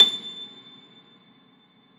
53e-pno25-A5.wav